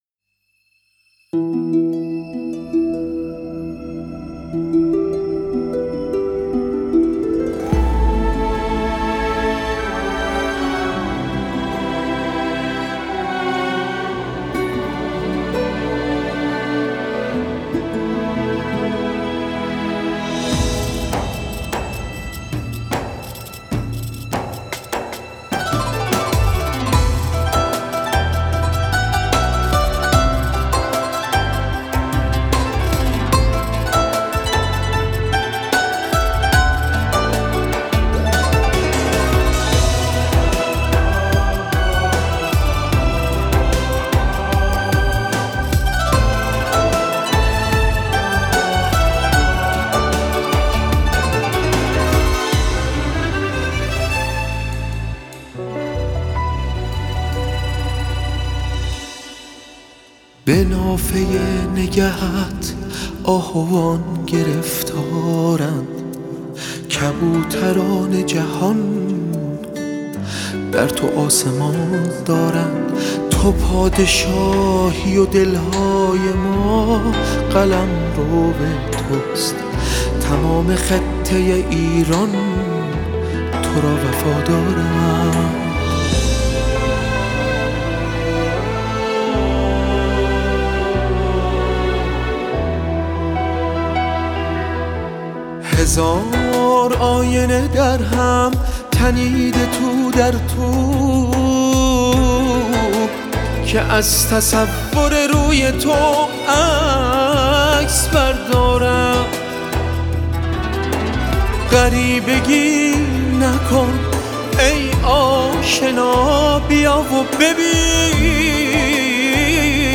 تیتراژ برنامه تلویزیونی